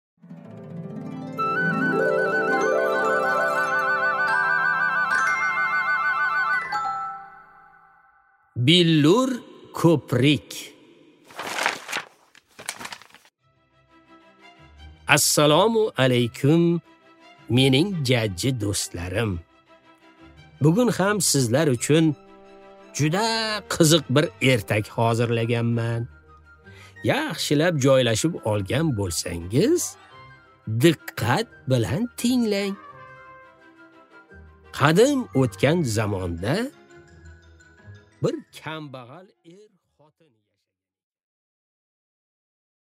Аудиокнига Billur ko'prik | Библиотека аудиокниг